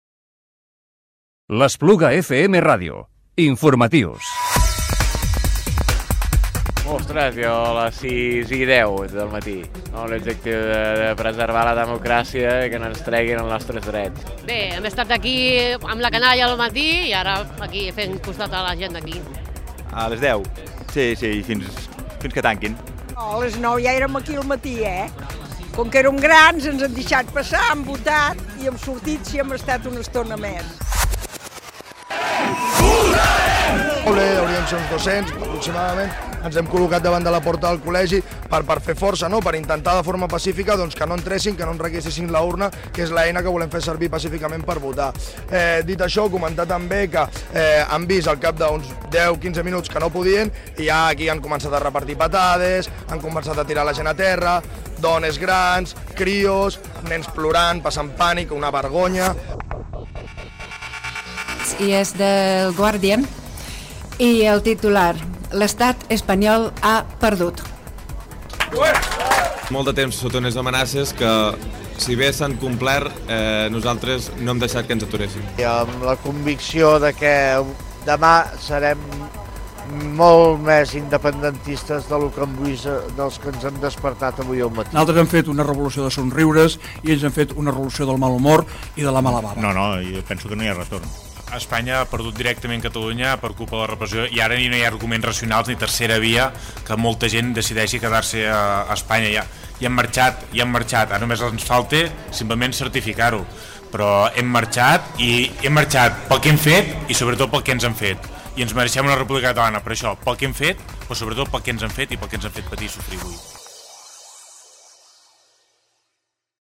Aquest dilluns no hem locutat el resum de la jornada en un minut. Marcats pels fets d’aquest diumenge, avui l’espai és de 2 minuts però només hi posem les veus d’alguns dels protagonistes d’aquest 1 d’octubre.